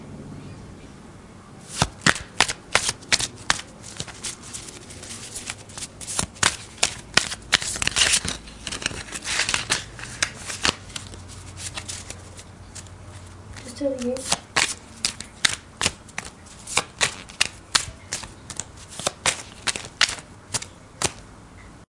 描述：我试图尽可能快地拍打。
Tag: 手指 快速捕捉 捕捉